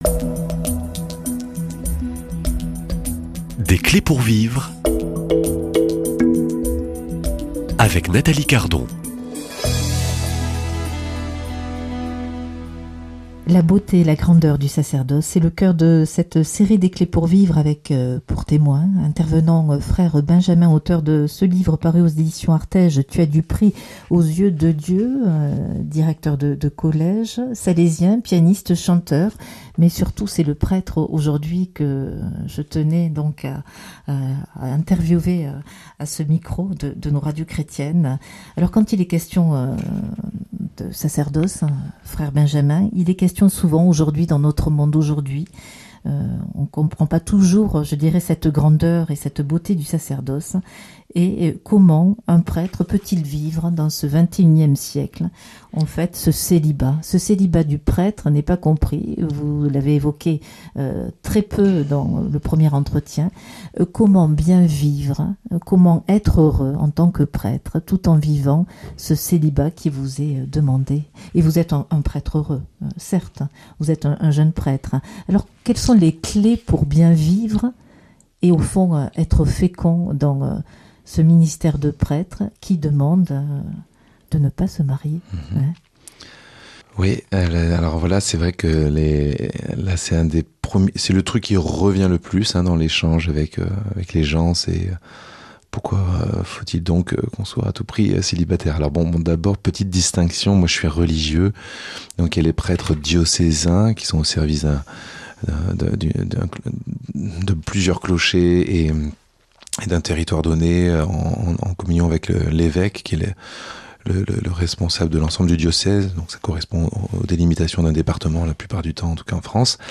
Accueil \ Emissions \ Foi \ Témoignages \ Des clés pour vivre \ Je veux des prêtres qui regardent vers moi !